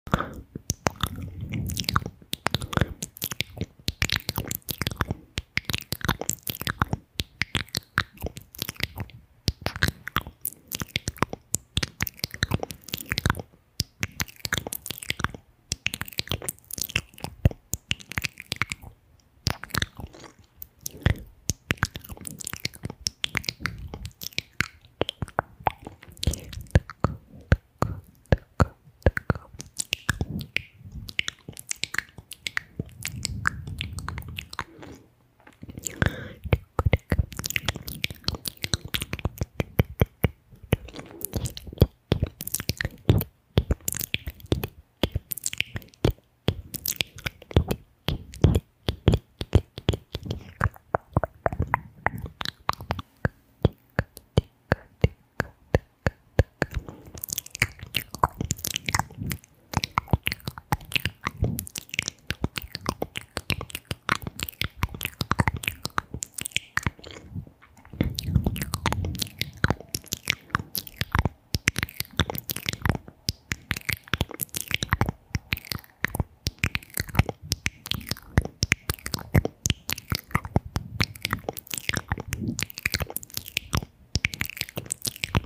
Asmr sons de boca e sound effects free download
Asmr sons de boca e beijinhos no microfone